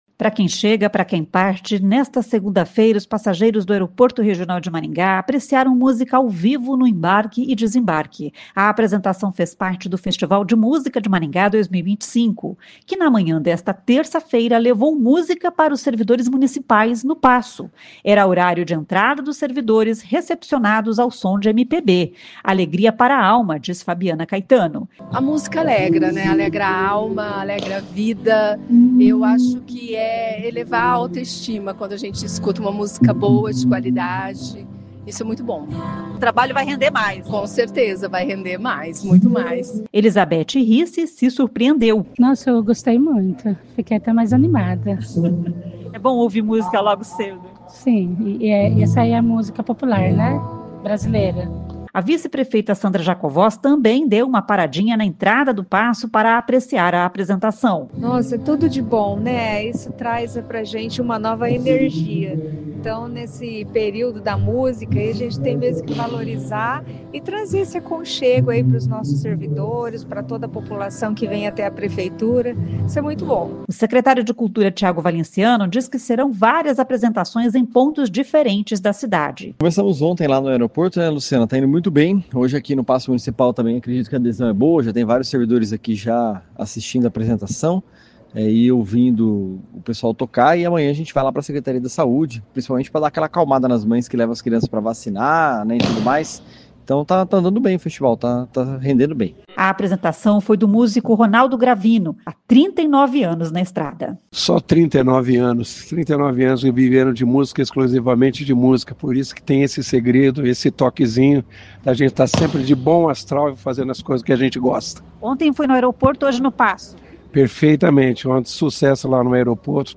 Nessa segunda-feira (13), os passageiros do aeroporto regional de Maringá apreciaram música ao vivo no embarque e desembarque.
Era horário de entrada dos servidores, recepcionados ao som de MPB.